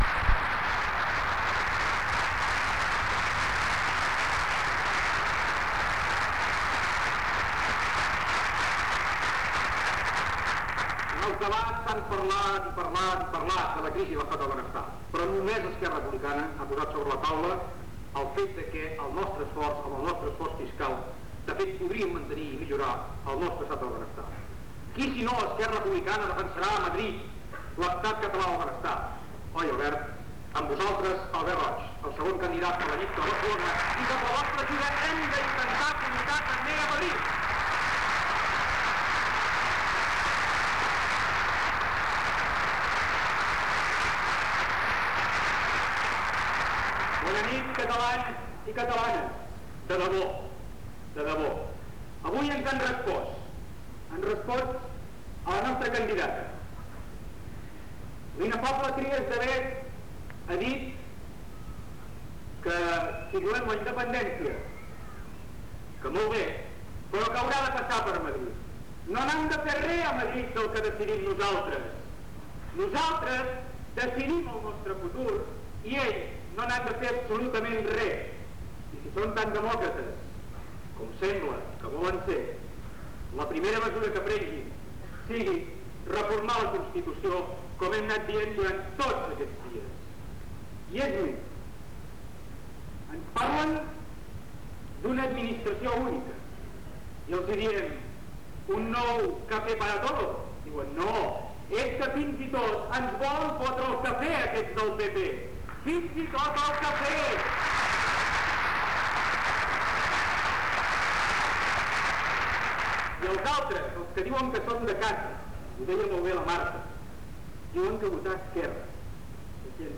Corpus Oral de Registres (COR). POL1. Míting polític